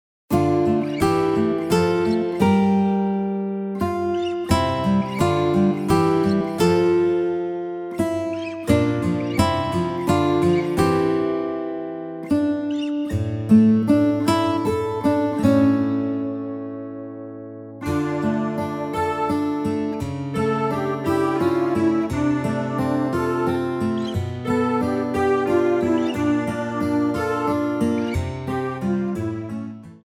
Rubrika: Pop, rock, beat
Karaoke
Hudební podklady AUDIO a VIDEO